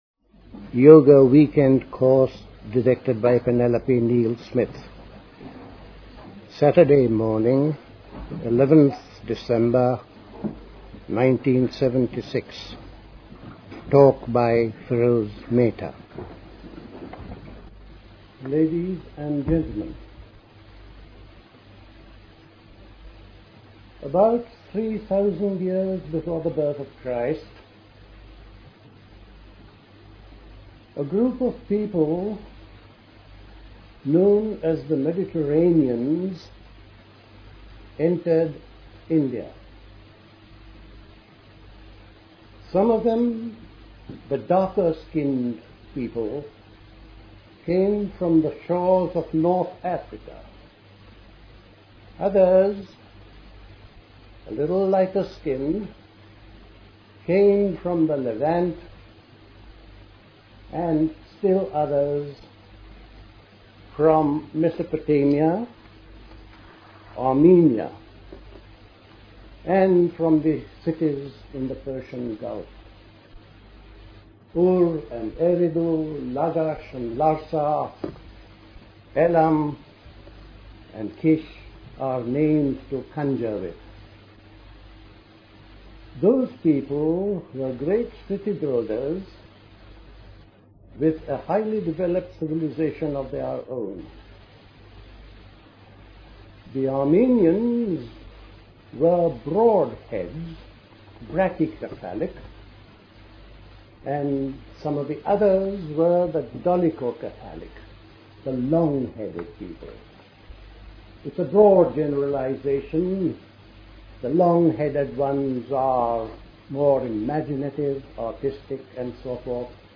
A talk
at Missenden Abbey, Great Missenden, Buckinghamshire